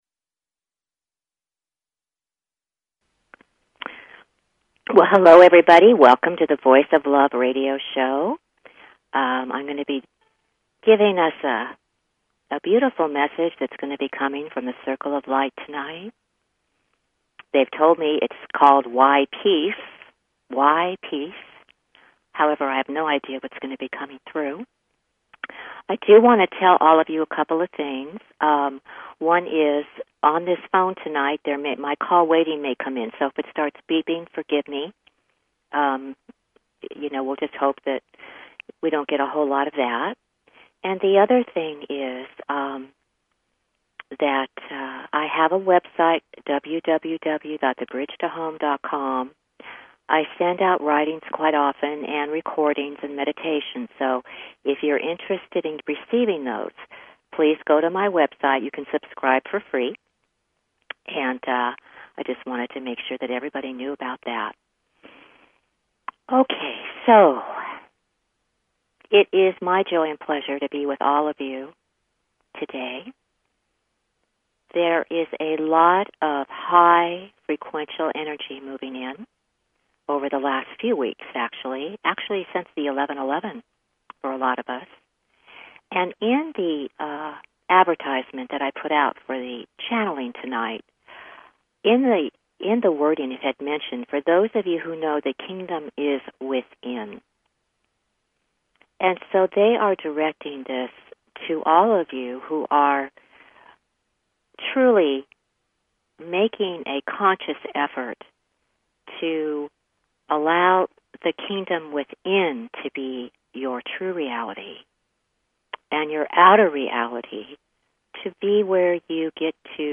Talk Show Episode, Audio Podcast, The_Voice_Of_Love and Courtesy of BBS Radio on , show guests , about , categorized as